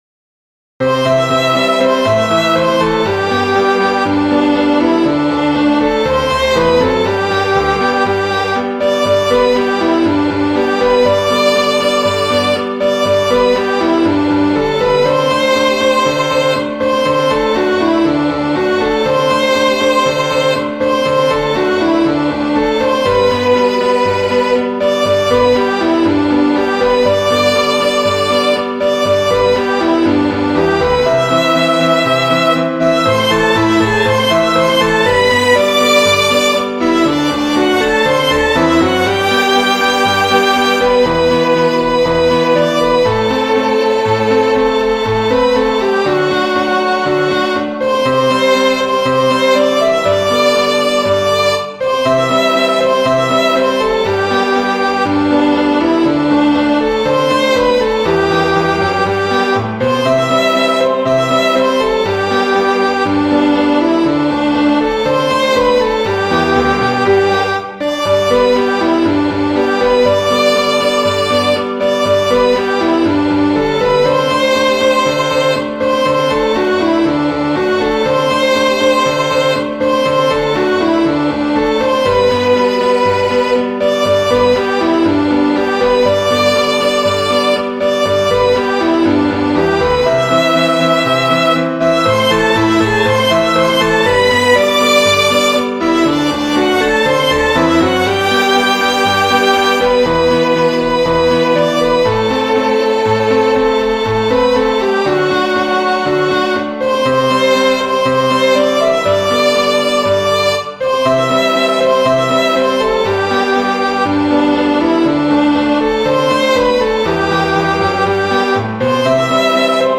3  MARCHAS POPULARES para 2026